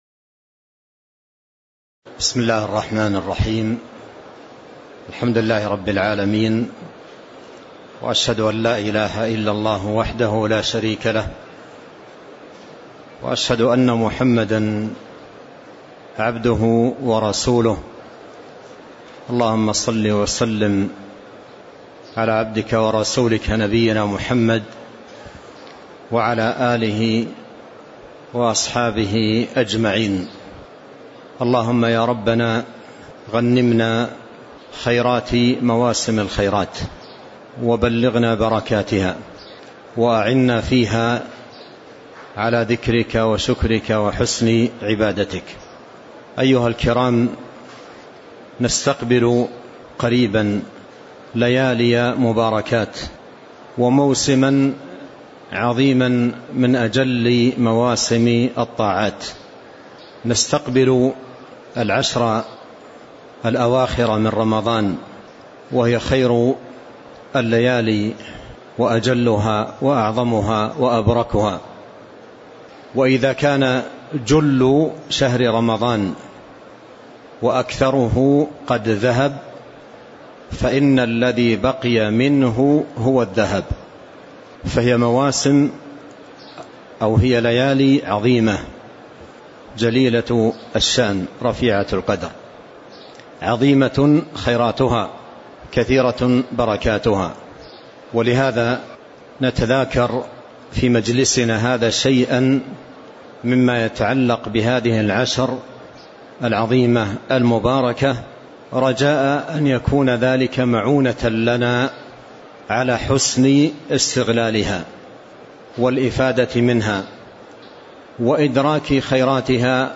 تاريخ النشر ١٨ رمضان ١٤٤٠ هـ المكان: المسجد النبوي الشيخ